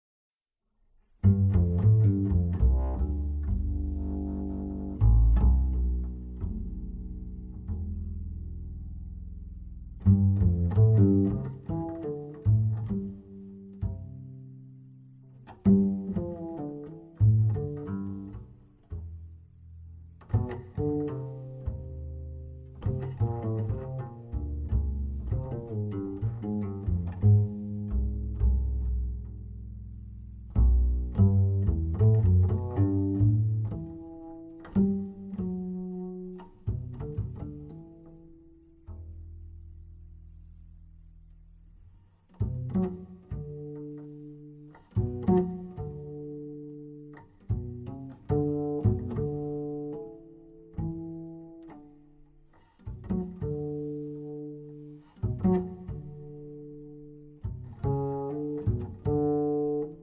piano
drums
bass